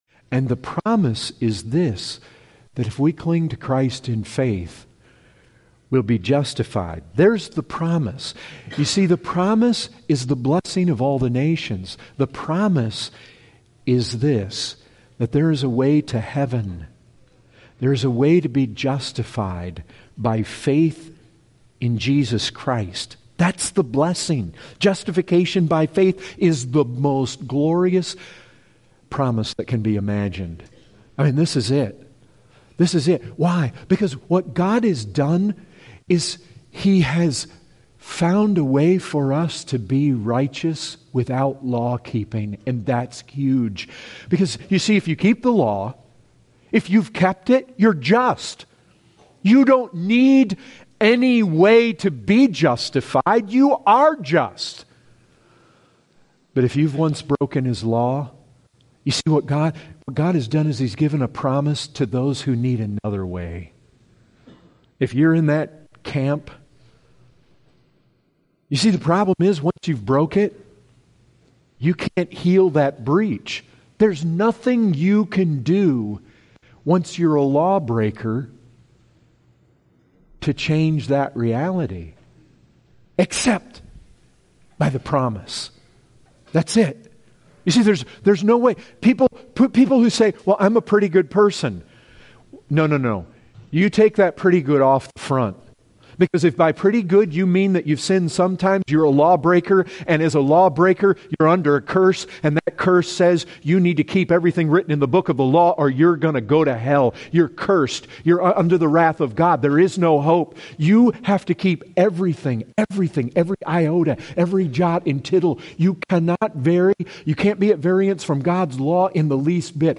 Excerpt taken from the full sermon, “People of Promise“.